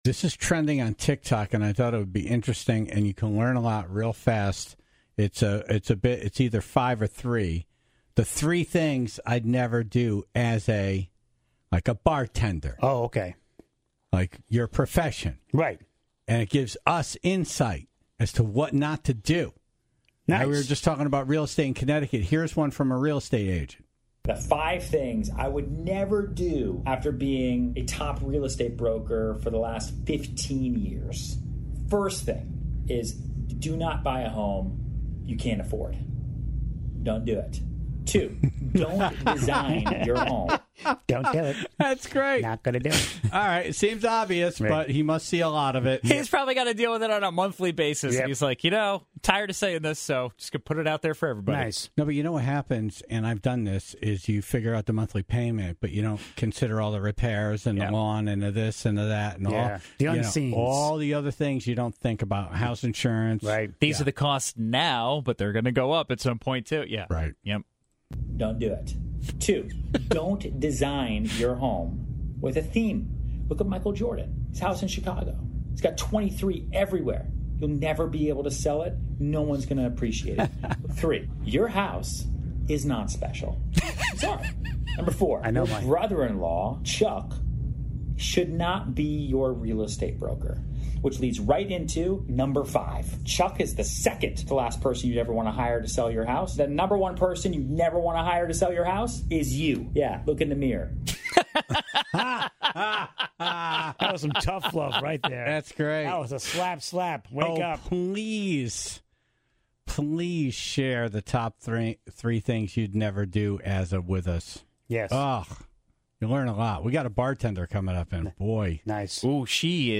They heard from a bartender, a real estate agent, and took live calls